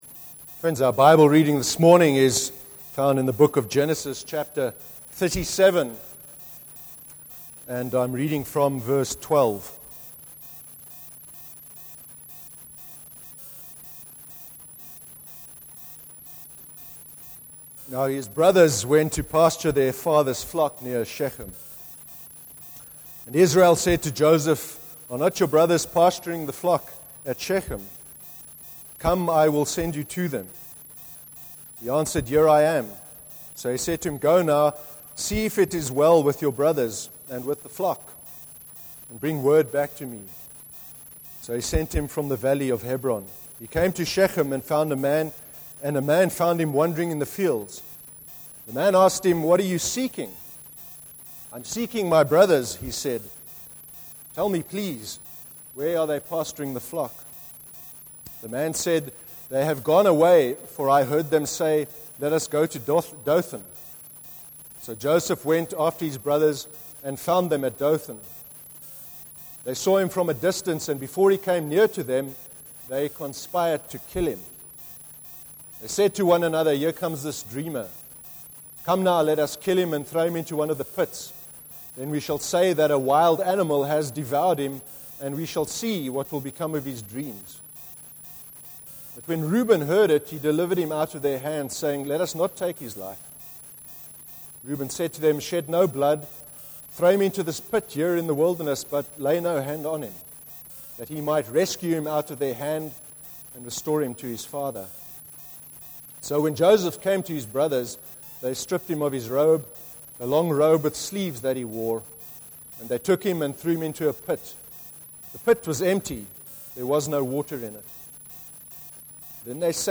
18/08/13 sermon – The problem of evil (Genesis 37:12-36)